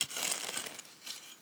Babushka / audio / sfx / Farming / SFX_Harke_03_Solo.wav
SFX_Harke_03_Solo.wav